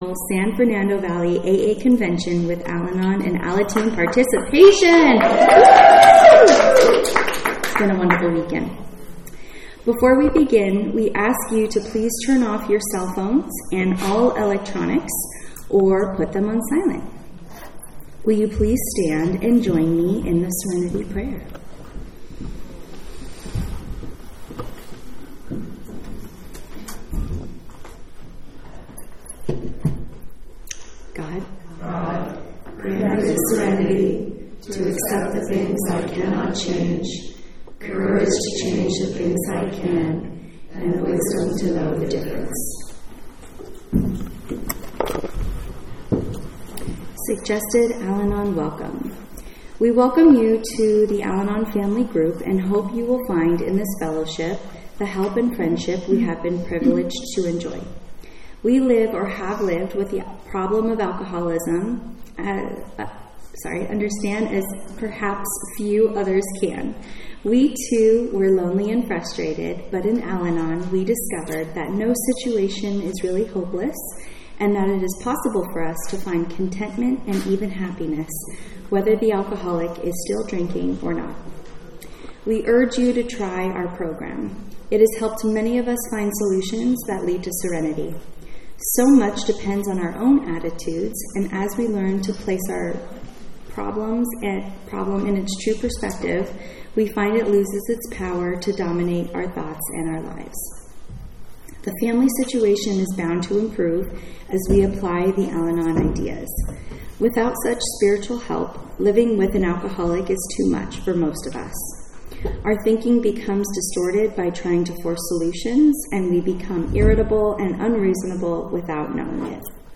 49th San Fernando Valley Alcoholics Anonymous Convention